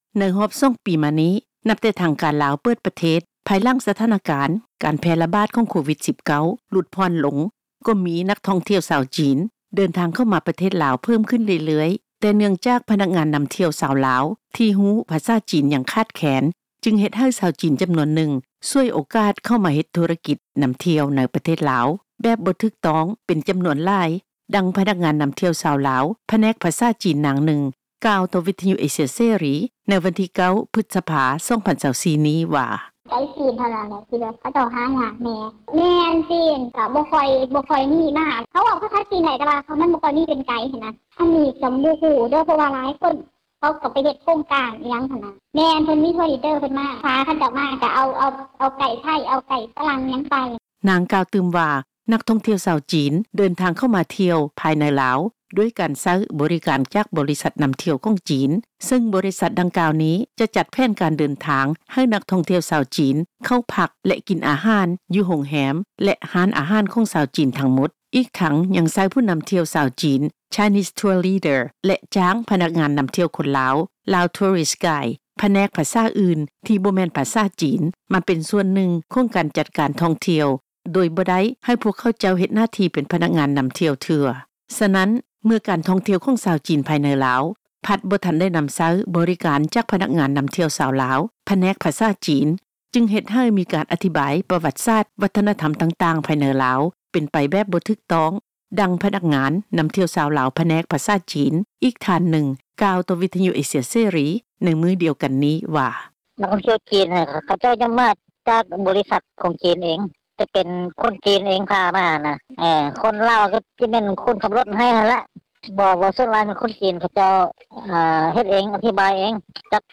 ດັ່ງ ພະນັກງານນໍາທ່ຽວຊາວລາວ ຜະແນກພາສາຈີນ ນາງໜຶ່ງ ກ່າວຕໍ່ ວິທຍຸເອເຊັຽເສຣີ ໃນມື້ວັນທີ 9 ພືດສະພາ 2024 ນີ້ວ່າ
ດັ່ງ ພະນັກງານນໍາທ່ຽວ ຜແນກພາສາອັງກິດ ທ່ານໜຶ່ງ ກ່າວວ່າ:
ດັ່ງ ເຈົ້າໜ້າທີ່ ທີ່ເຮັດວຽກດ້ານການທ່ອງທ່ຽວໂດຍກົງ ທ່ານໜຶ່ງກ່າວວ່າ: